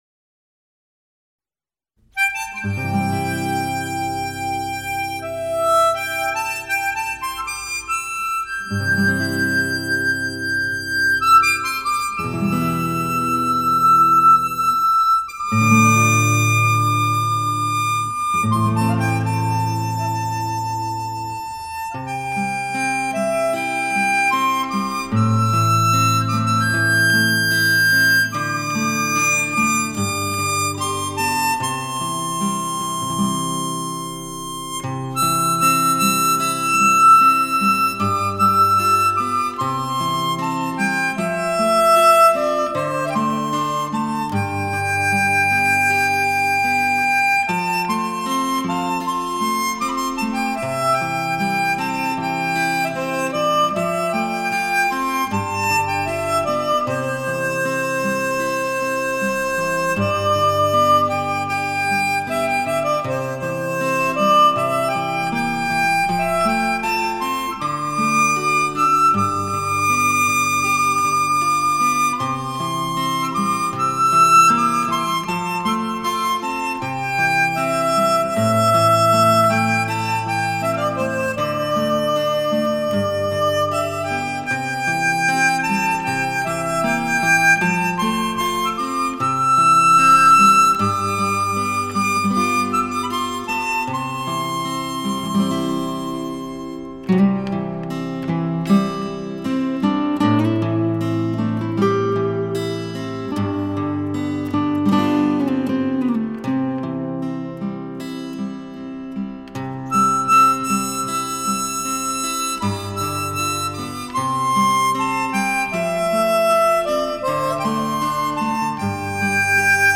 专辑风格：轻音乐、口琴